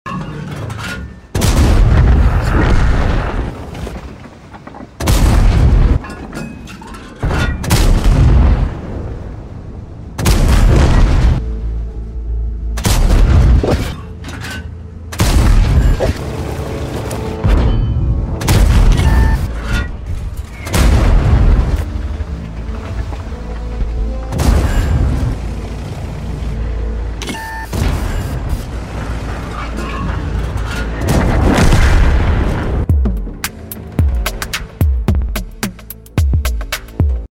restless gameplay